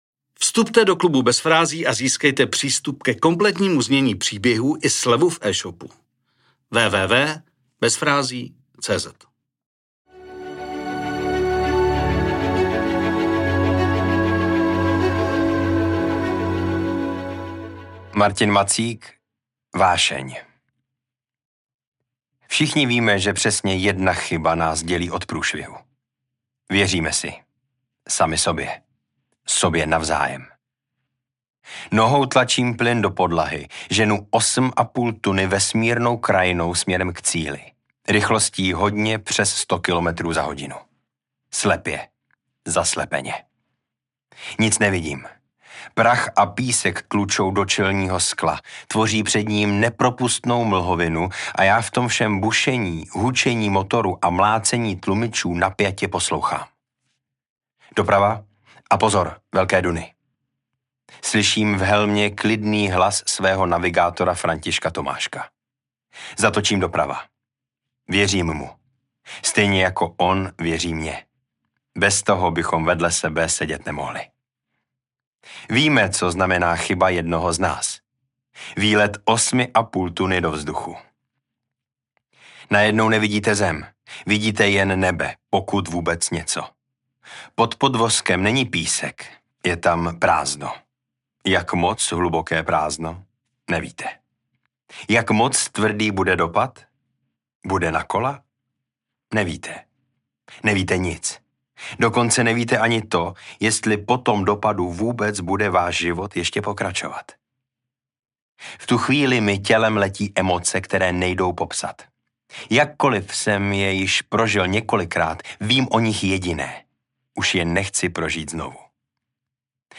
Připomeňte si vyprávění Martina Macíka .